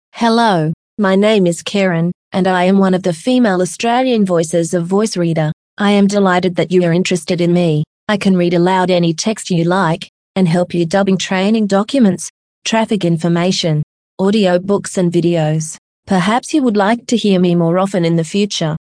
Voice Reader Home 22 Englisch-Australisch – weibliche Stimme (Karen)
Voice Reader Home 22 ist die Sprachausgabe, mit verbesserten, verblüffend natürlich klingenden Stimmen für private Anwender.